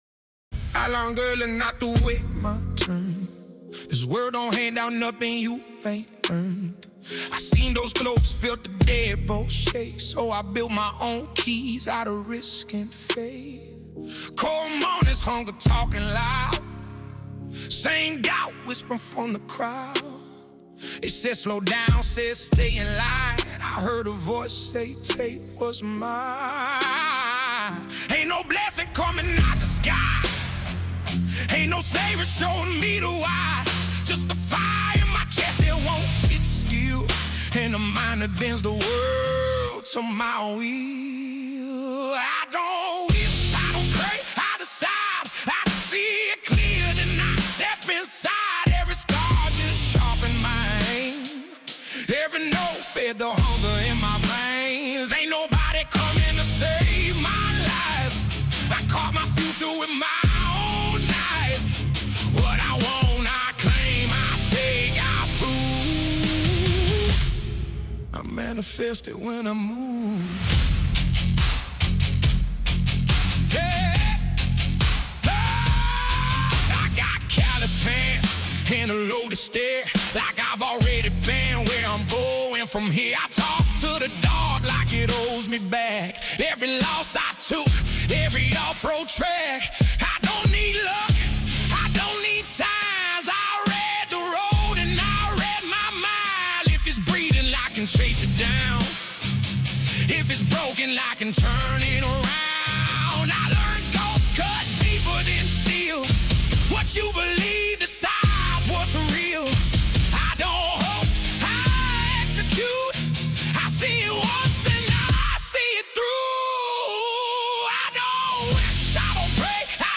Official Studio Recording
a fusion of 140 bpm cinematic soul and gritty outlaw country